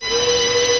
chargejump.wav